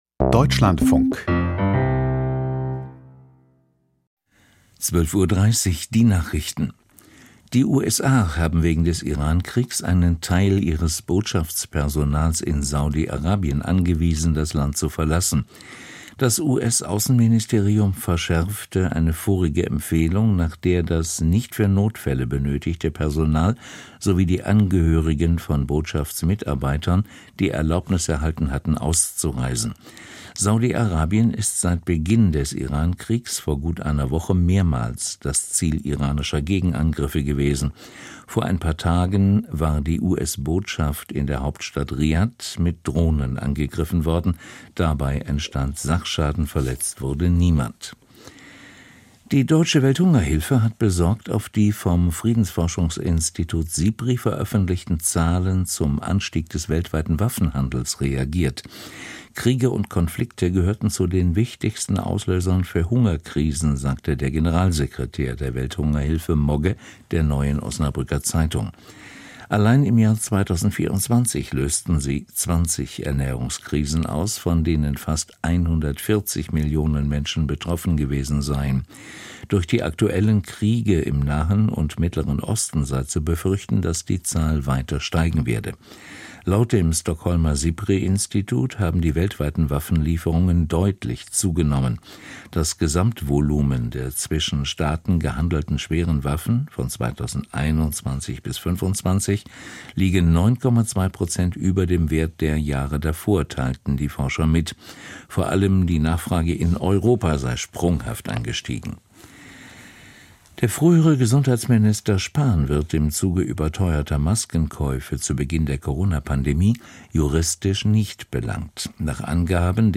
Aus der Deutschlandfunk-Nachrichtenredaktion.